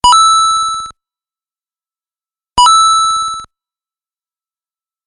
Efectos de sonido